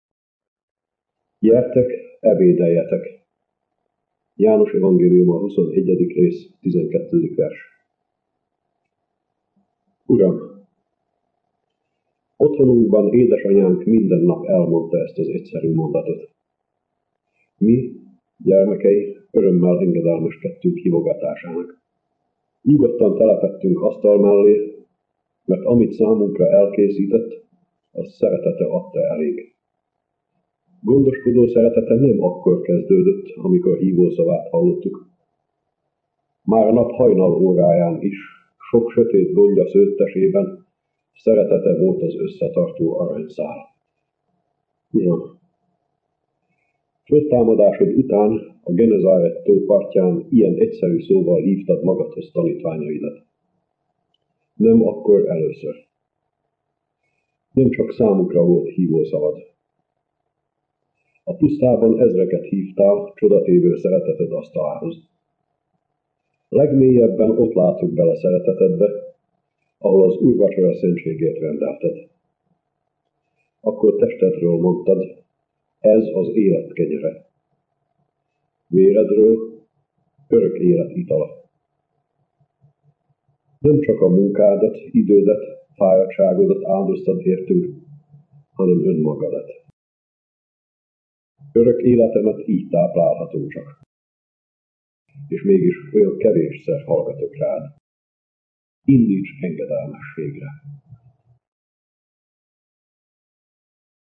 Ordass Lajos: ÚtravalóORDASS LAJOS (1901–1978) áhítatos könyve, az Útravaló, az egyházi év minden napjára tartalmaz egy rövid elmélkedést, melyben a püspök minden reggel Urával beszélgetett, hogy erőt merítsen az új nap küzdelmeihez. Tíz útravalót hallhatnak Ordass Lajos elmondásában.